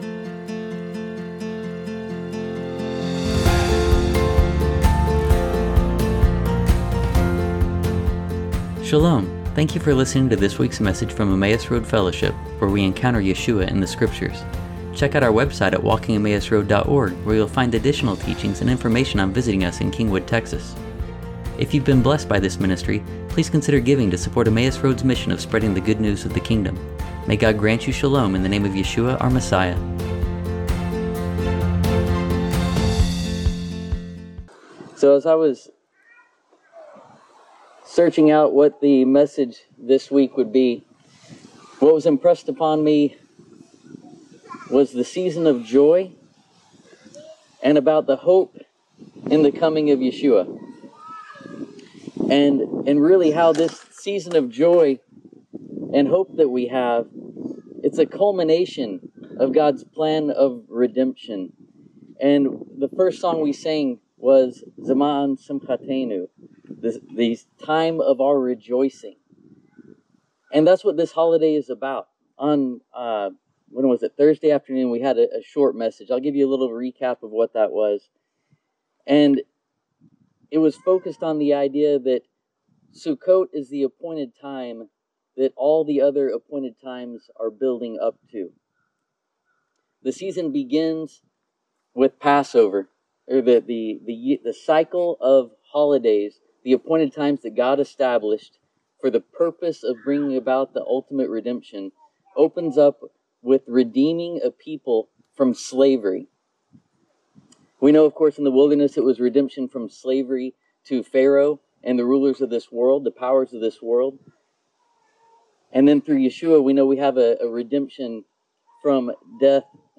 Shabbat Chol HaMo’ed Sukkot